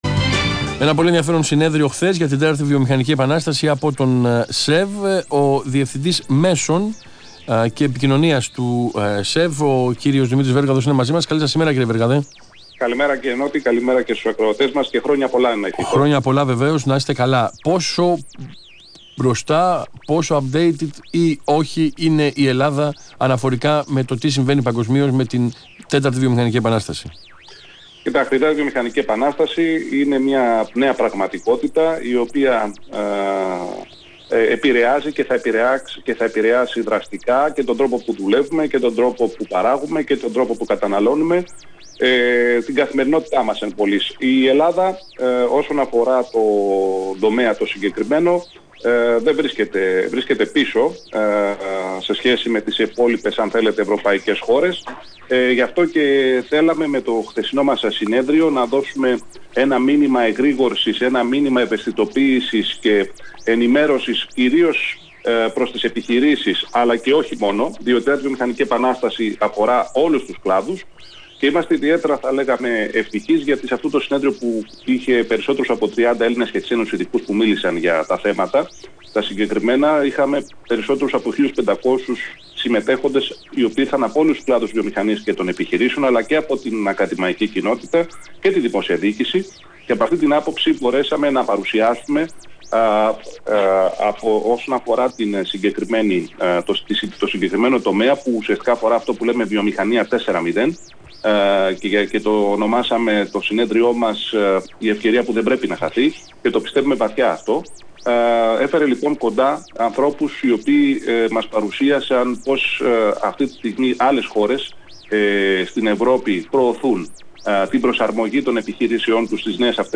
Συνέντευξη
στον Ρ/Σ ΑΘΗΝΑ, 9.84